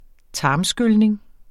Udtale [ ˈtɑːmˌsgølneŋ ]